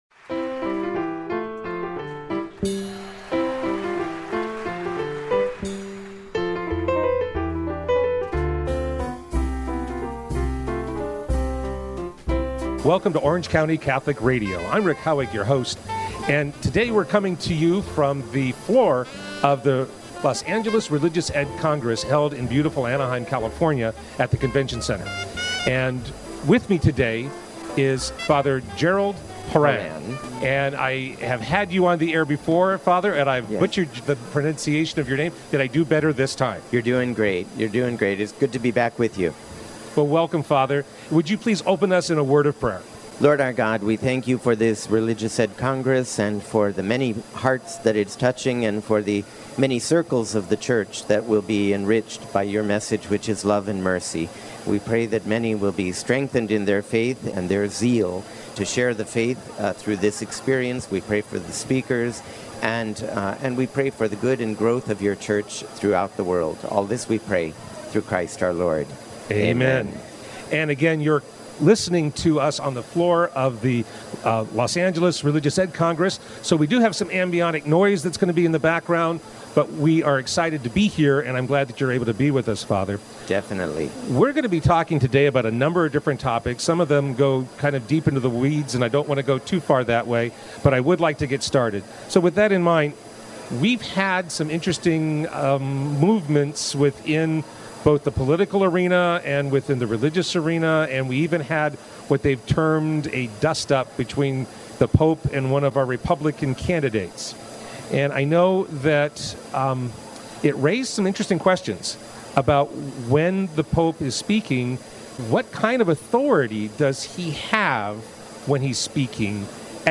interviews guests on a variety of topics.
live at the Religious Education Congress